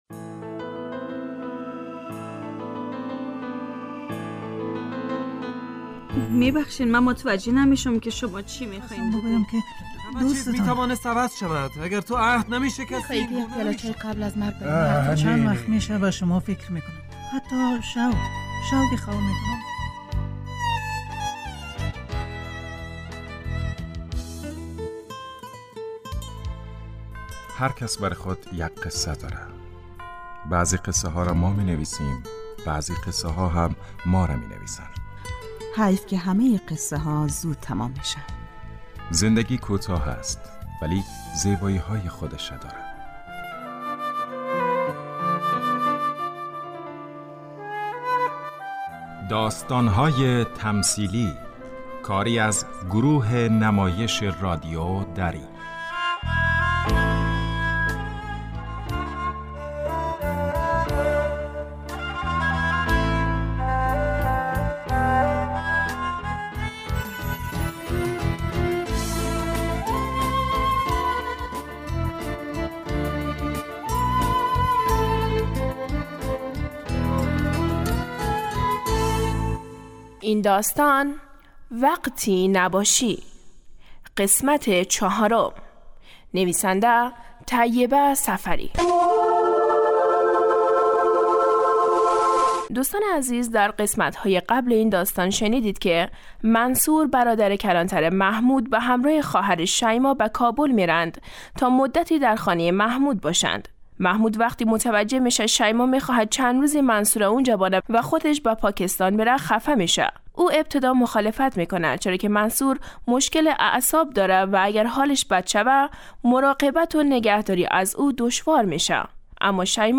داستان تمثیلی